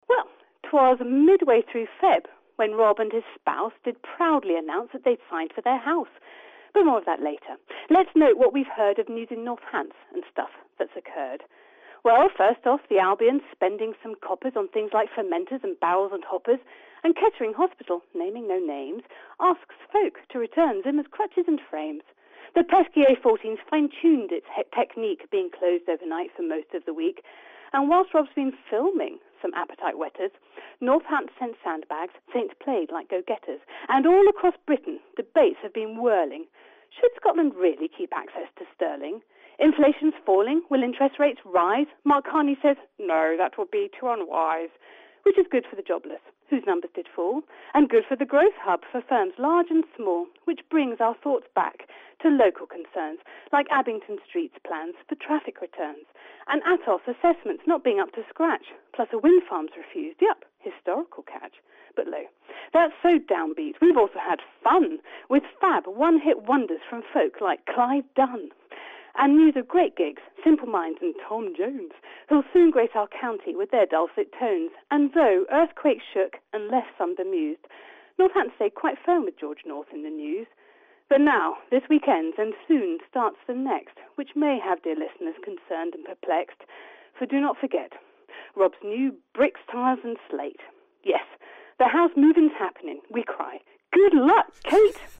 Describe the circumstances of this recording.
on BBC Radio Northampton this week in the form of a poem.